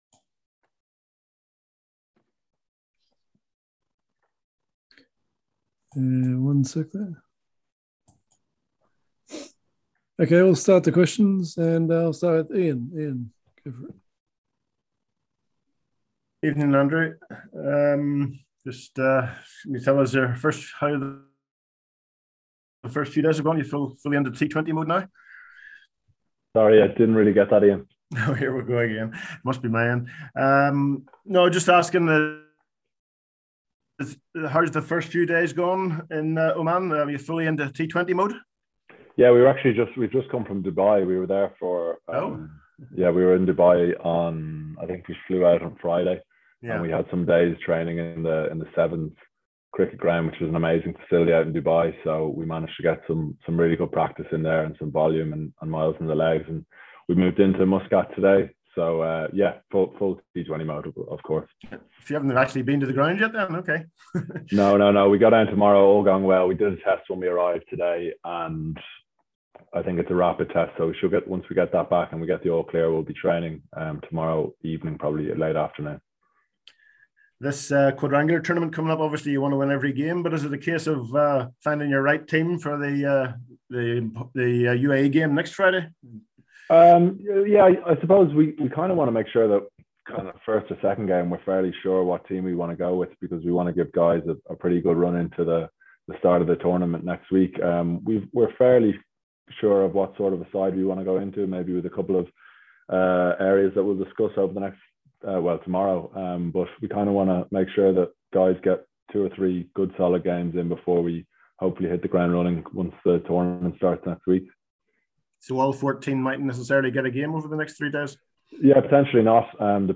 A virtual Press Conference with Andrew Balbirnie was held today ahead of the T20I Quadrangular Series in Oman starting on Friday.